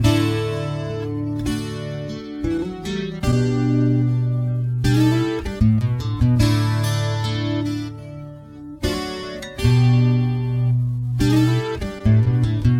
75Bpm吉他
描述：D调吉他
Tag: 75 bpm Acoustic Loops Guitar Acoustic Loops 2.15 MB wav Key : D